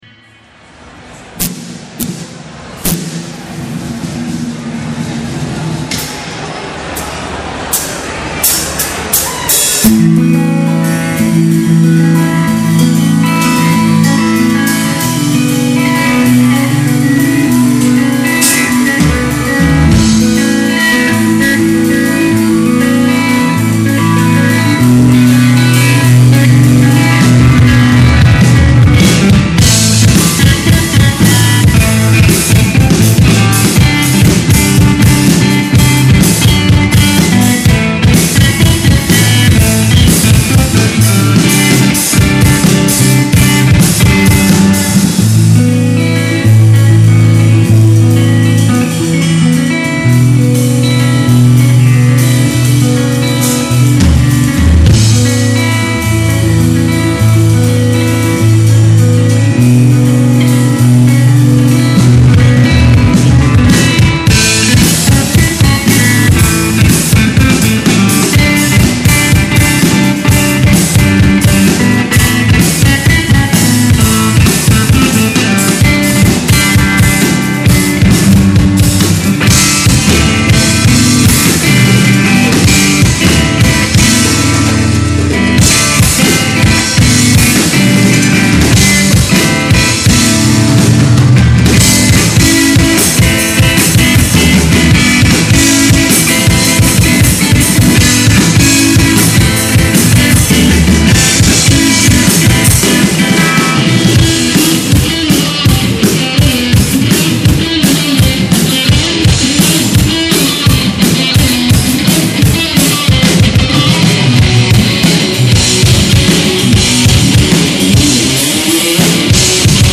- LIVE -
Chitarra
Batteria
( live )